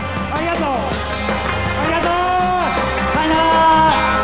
All sounds in WAV format are spoken by Yusaku himself (Also Andy Garcia and Michael Douglas).
At the end of his concert.
concert.wav